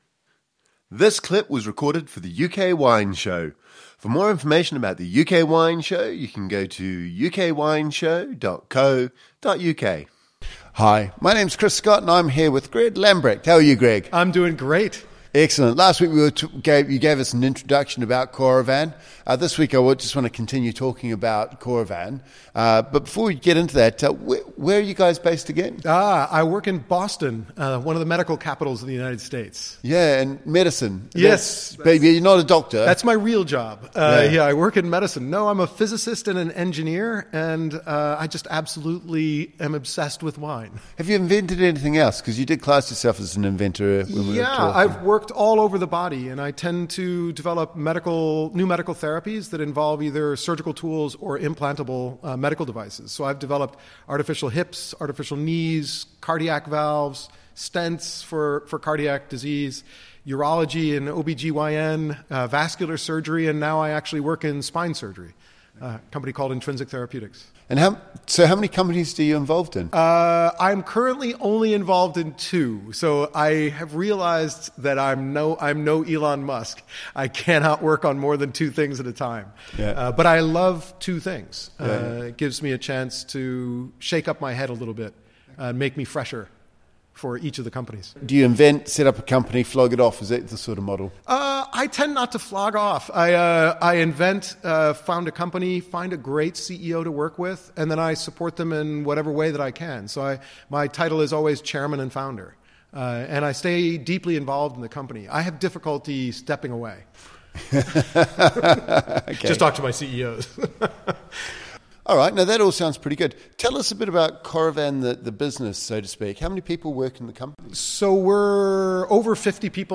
Listen to the end of the interview for tips and tricks to get the most out of your Coravin. They are now working on a new system which aims to expand the ways in which wine can be sold, no details available as yet.